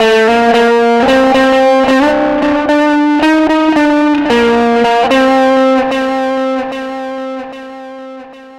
Track 15 - Guitar 03.wav